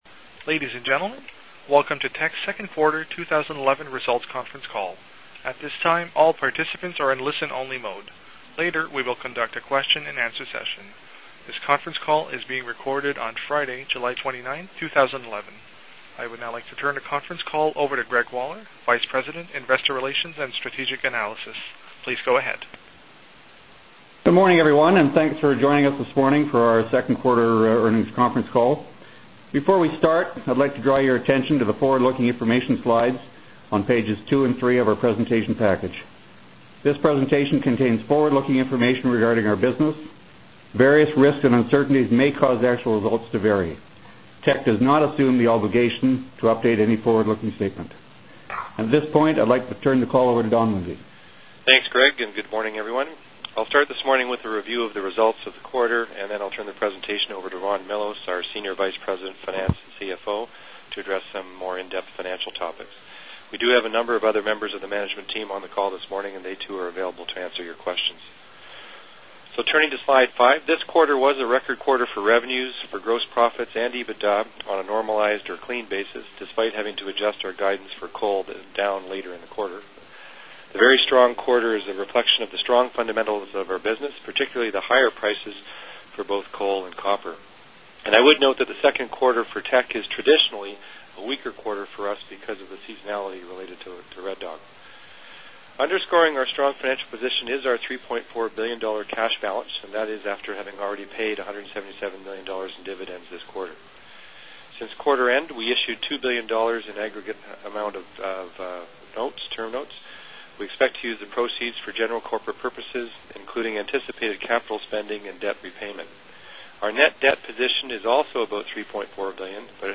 Q2-2011-Report-Conference-Call-Audio.mp3